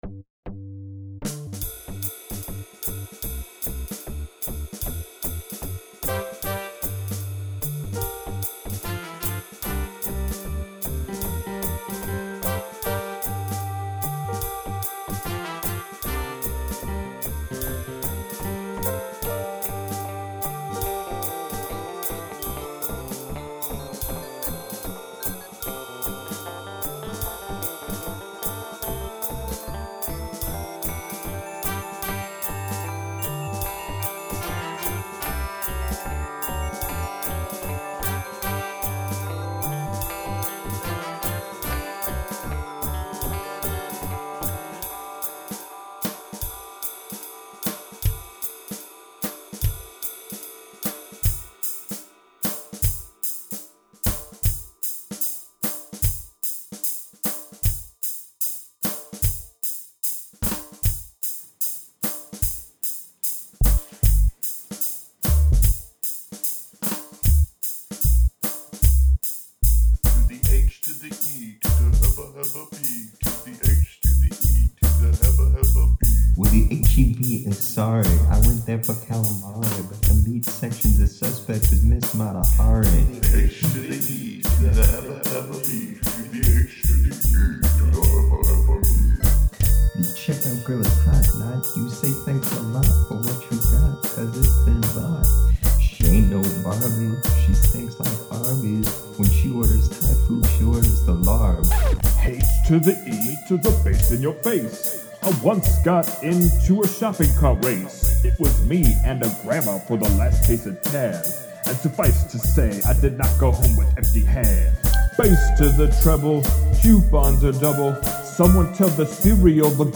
I want to keep the swing feel under it …
You won’t – can’t – begin to try to explain the horror that you feel listening to your father freestyle about a local grocery store over a bunch of his jazz musician friends trying to play hip hop music.
Dad-and-Friends-on-the-H.E.B.-Grocery.mp3